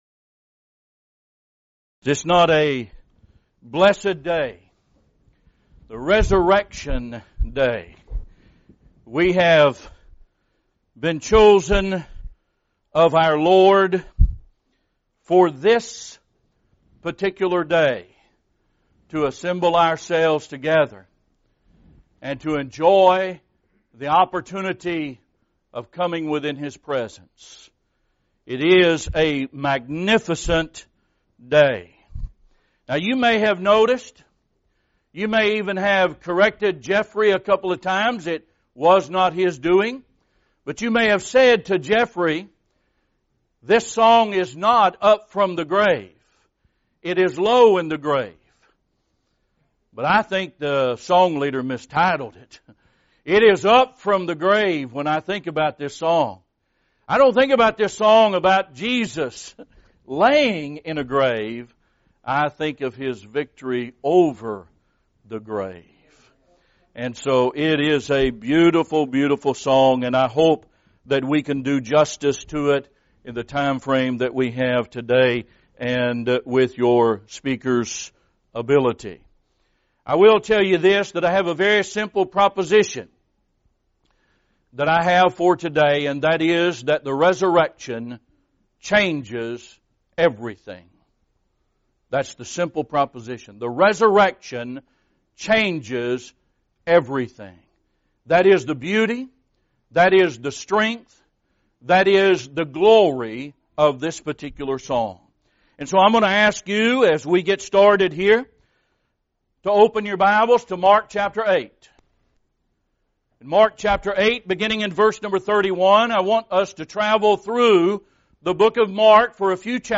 Event: 2018 Lovelady Lectures
this lecture